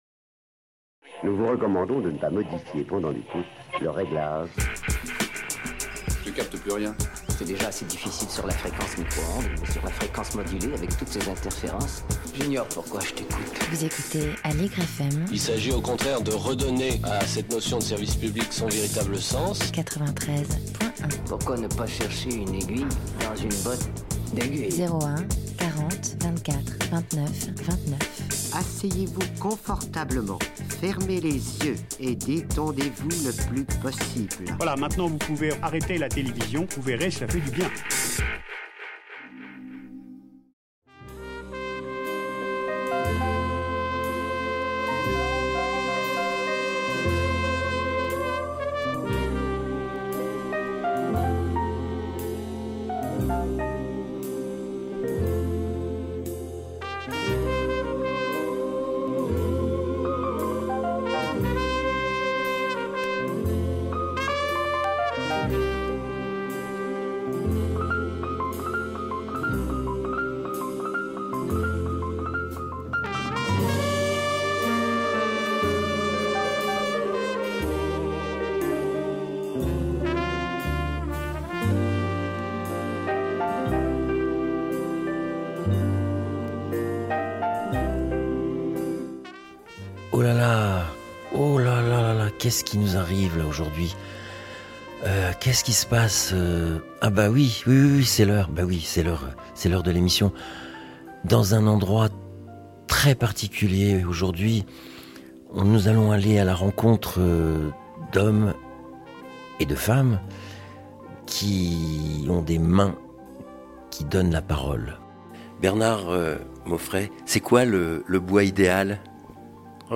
Les entretiens ont été réalisés dans leur atelier respectif installé au milieu d’une pépinière d’artisans au « 37 bis » à la Cour de l’industrie à Paris. Ils nous racontent leur parcours et le travail de création qu’ils réalisent avec passion.